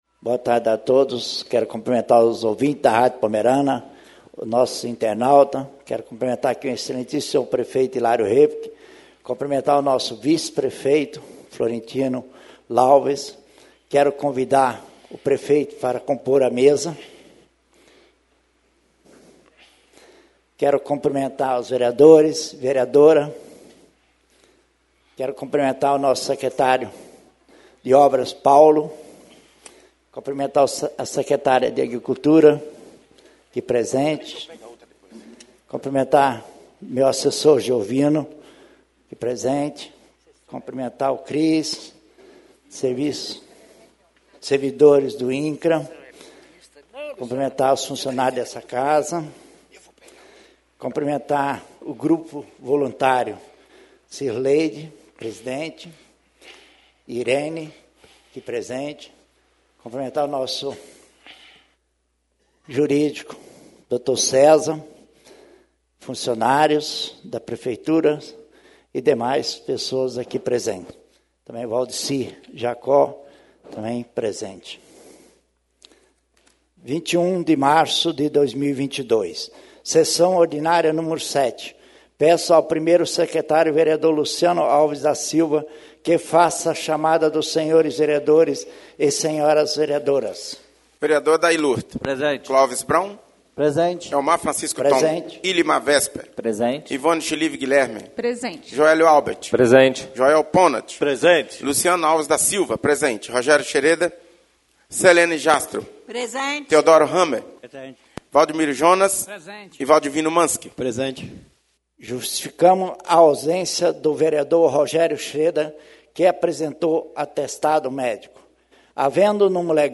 SESSÃO ORDINÁRIA Nº 7/2022